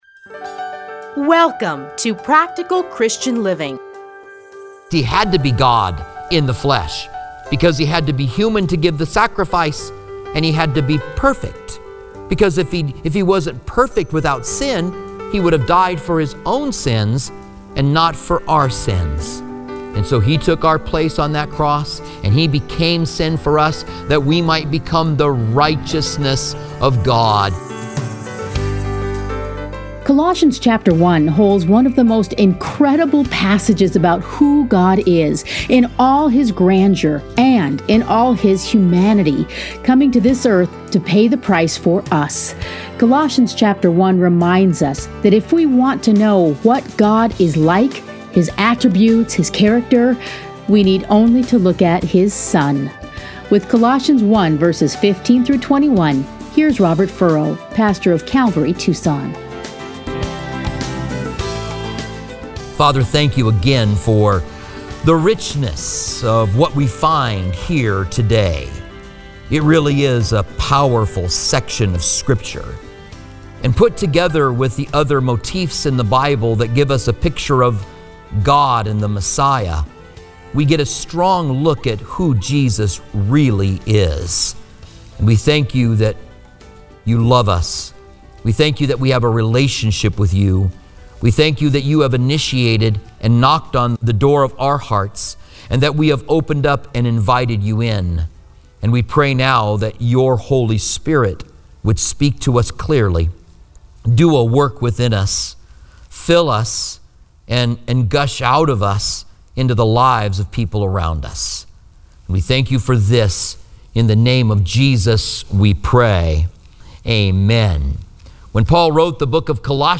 Listen here to a teaching from Colossians.